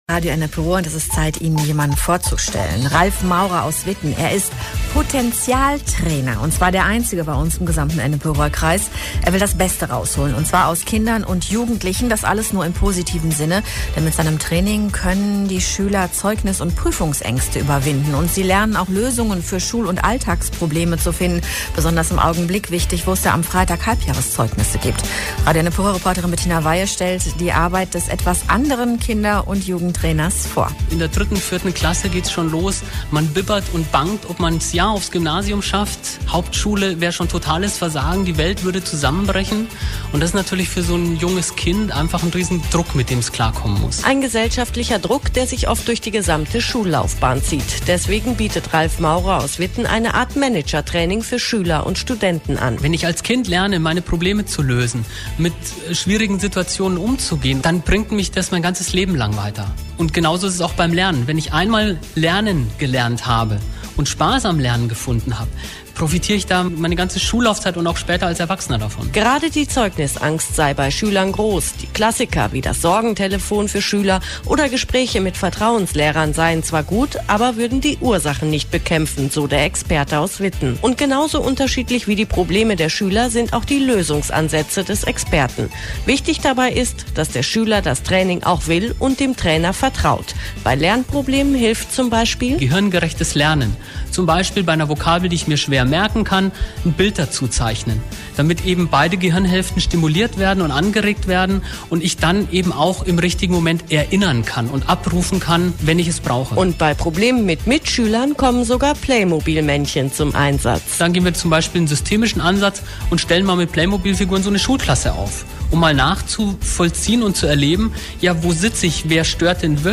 Radio (Ausschnitte):
Radiobericht über einen unserer Kinder- und Jugendcoaches in Witten.